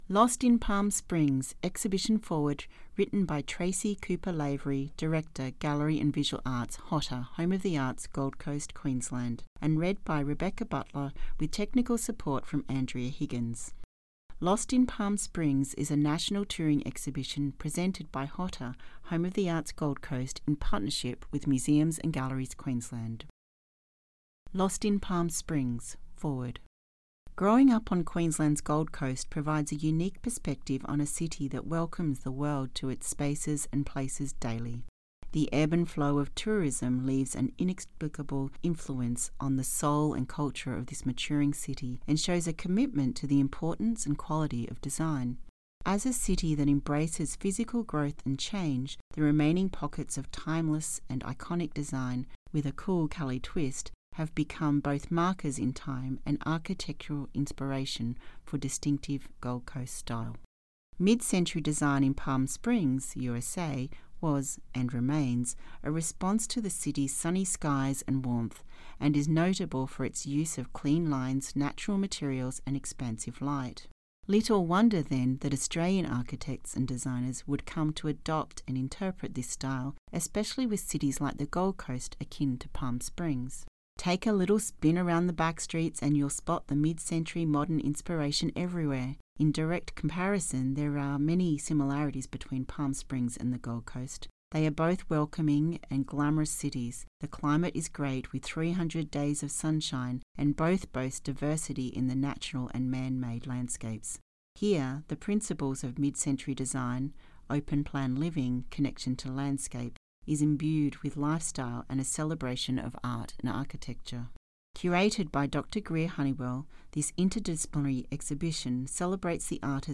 FINAL-LIPS-Audiobook.mp3